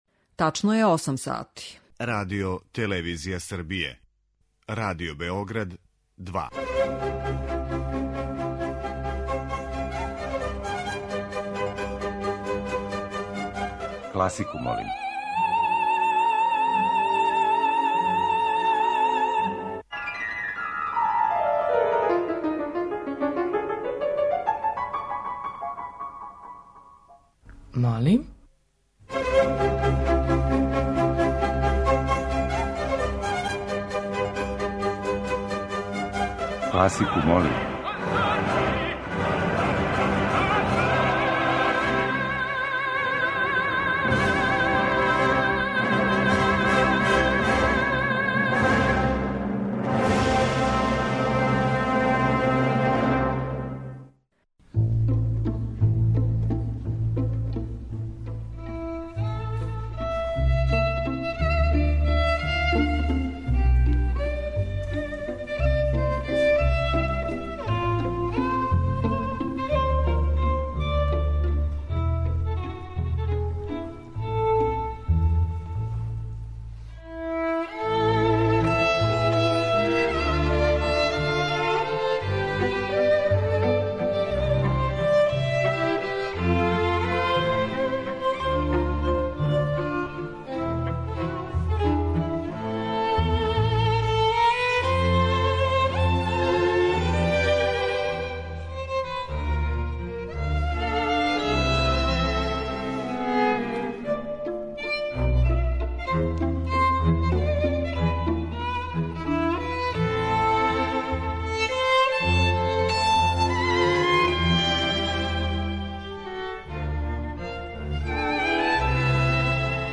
Оперске улоге лирских сопрана